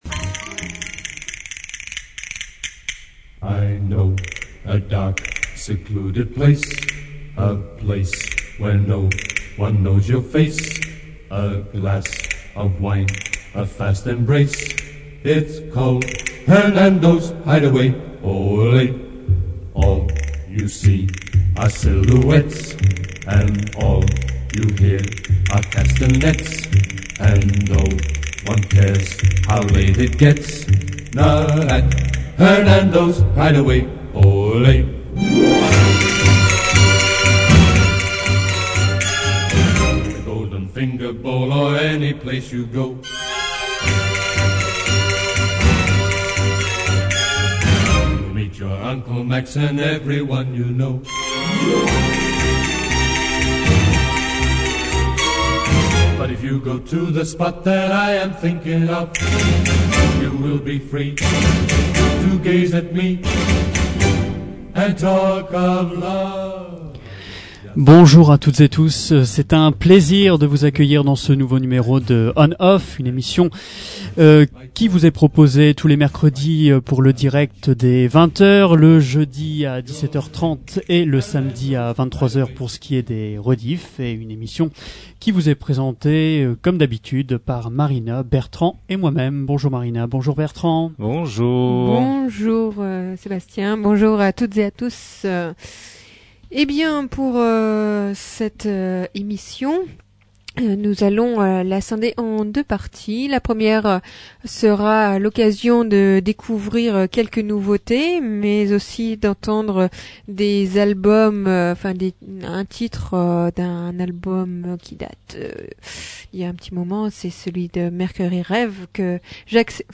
Après une programmation musicale modestement flamboyante, le crew On/Off a eu le plaisir de recevoir le groupe Belzeband pour sa première émission de radio.